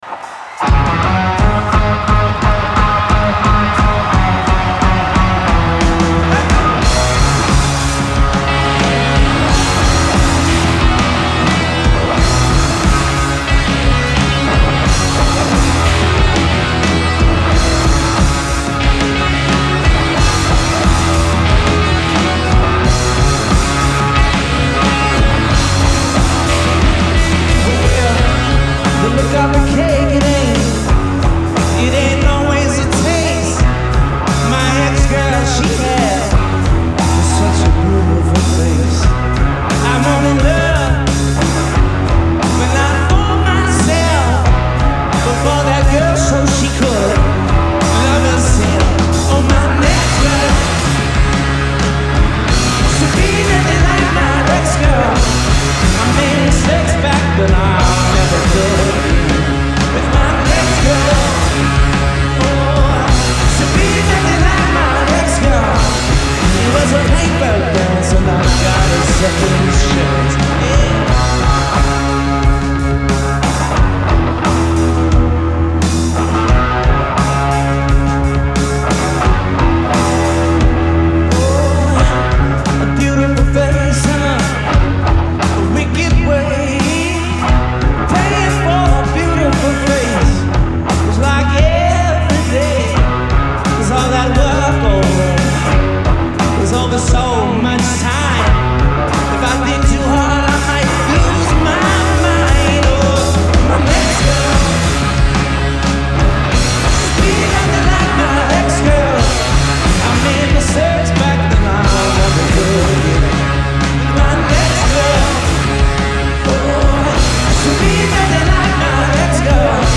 Genre : Alternative, Indie
Live in Portland, ME